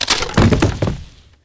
WoodSnap.ogg